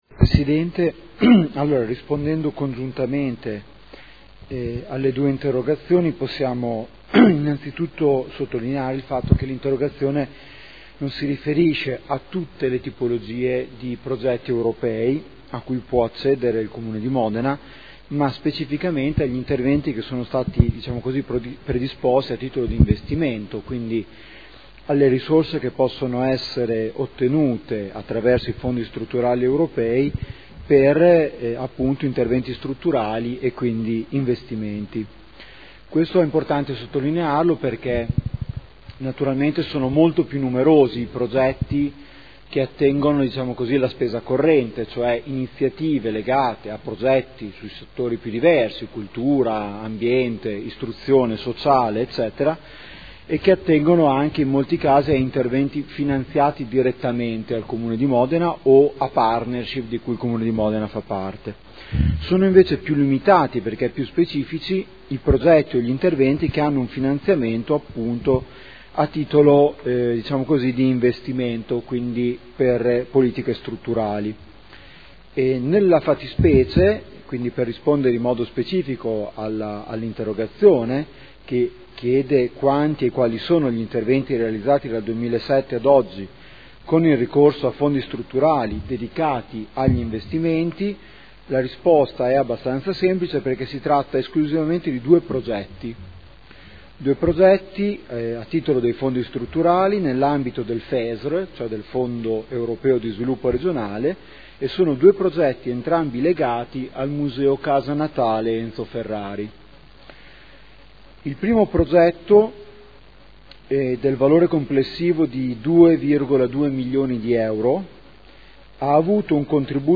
Seduta del 25/06/2012.